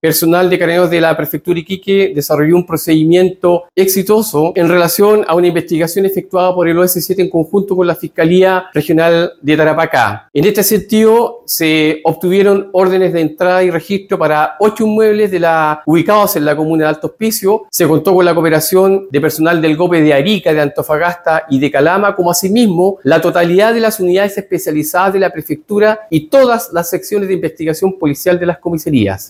El general Adrián Andrades Miranda, jefe de la Zona Tarapacá de Carabineros, destacó la coordinación entre las unidades policiales y la Fiscalía, además de la colaboración del GOPE de Arica, Antofagasta y Calama.